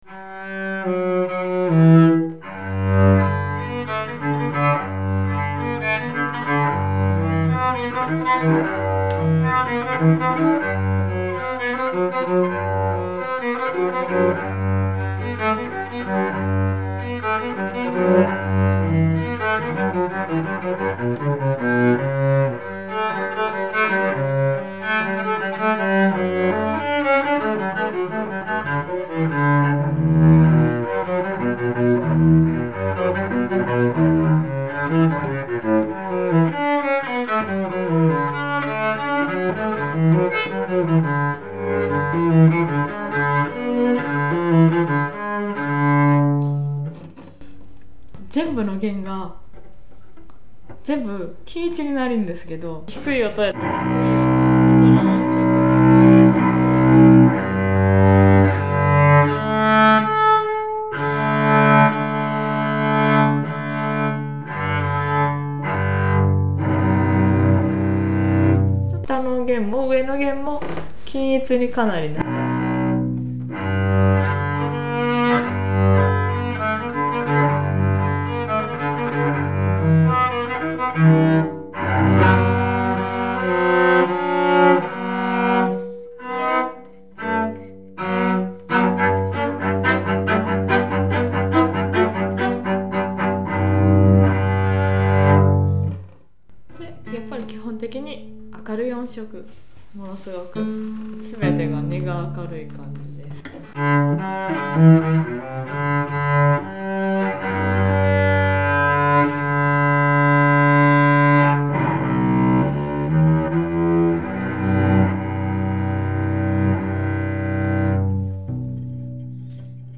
演奏者紹介)さんをお迎えし、試奏と以下のそれぞれのチェロの講評をして頂きました。